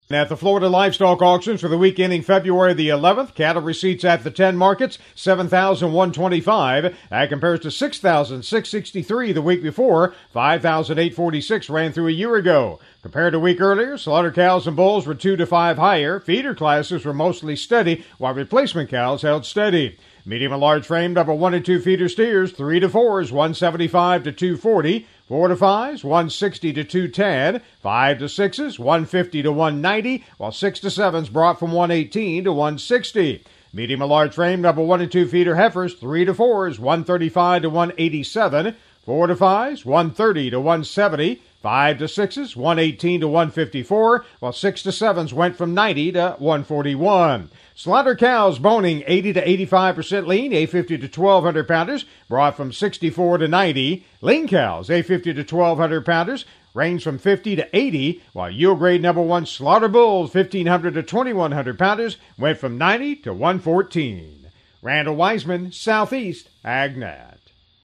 FL Weekly Livestock Market Report